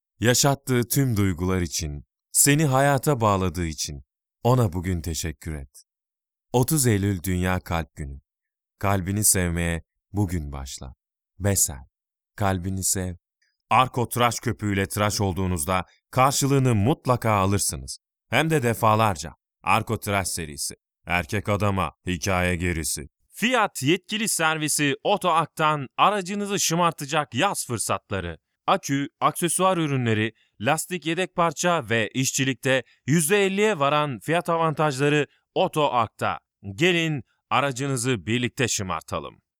Sprechprobe: Werbung (Muttersprache):
male voice over artist in turkish german english